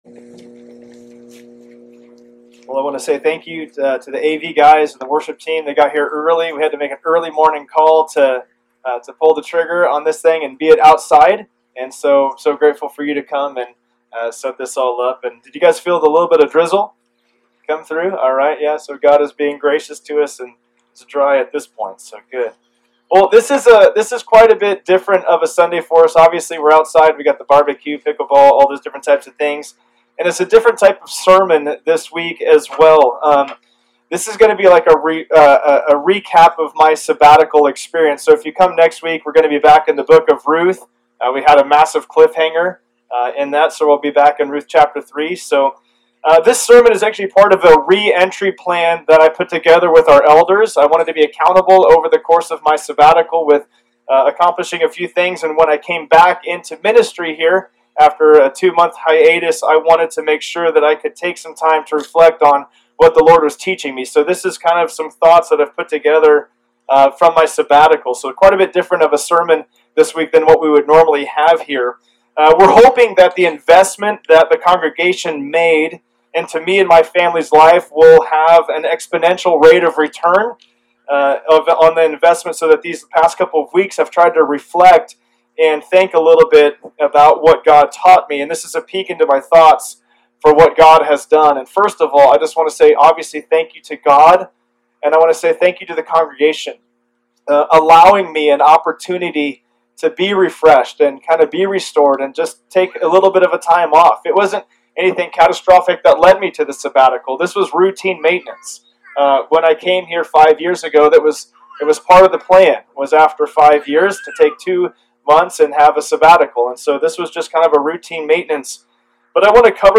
Sabbatical Recap Service Type: Sunday Service Download Files Notes « Ministry Kickoff 2025